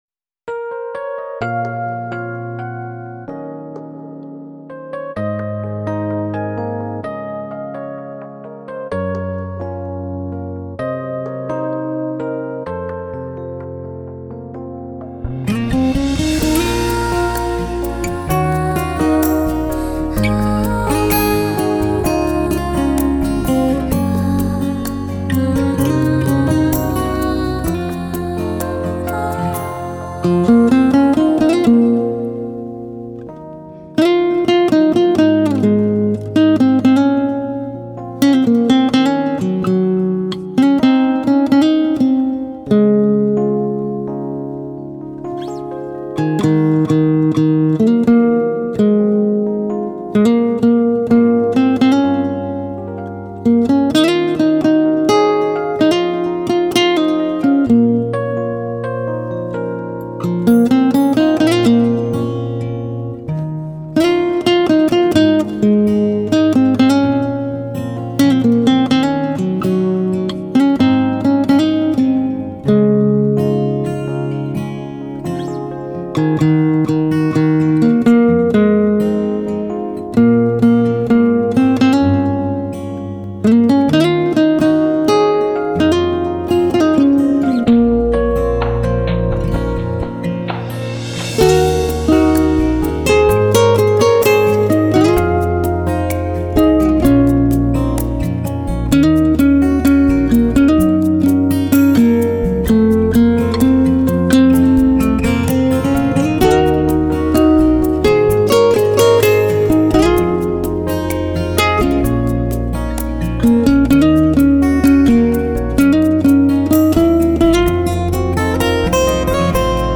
Nhạc Thư Giãn